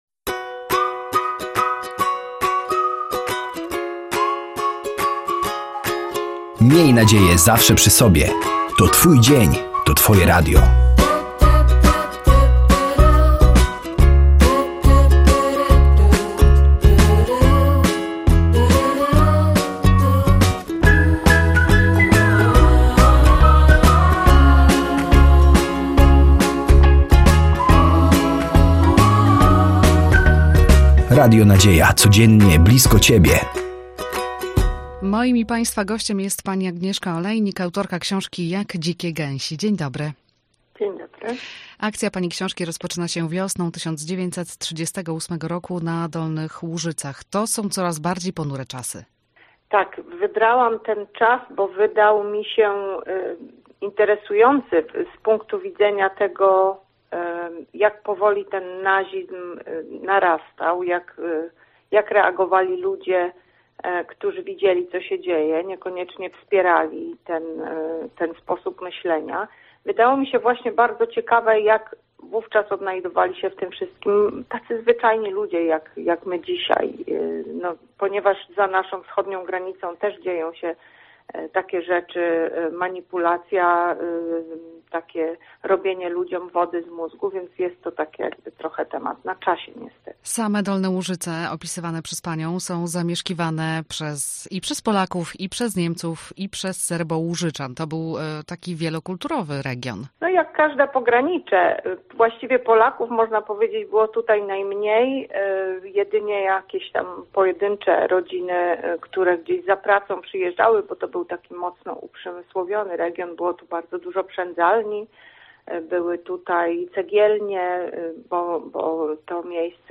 Zapraszamy od wysłuchania rozmowy